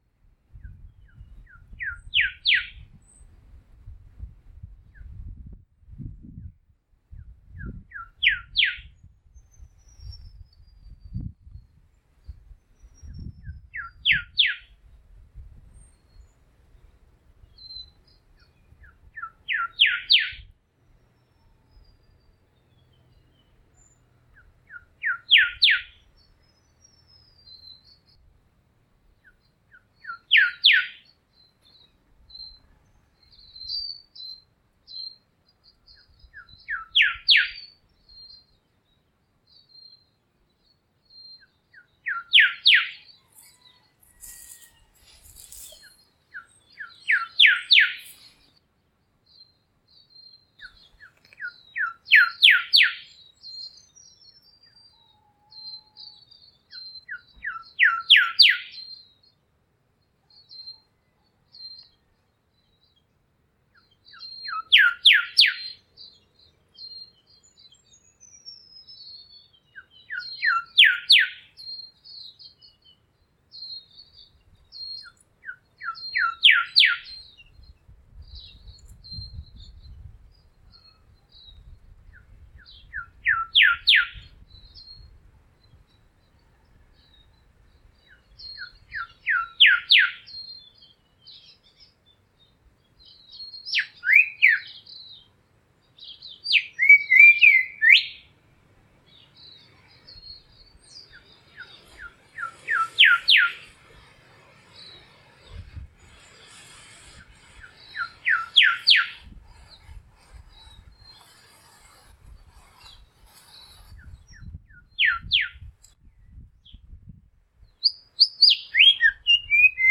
Australian Golden Whistler
Norfolk Island Botanic Gardens, Norfolk Island, South Pacific Ocean, Australia
Pachycephala pectoralis xanthoprocta
Whistler Australian Golden (xanthoprocta) BOTANIC GARDENS NF AUS call song [A] ETSJ_LS_71907 full (edit).mp3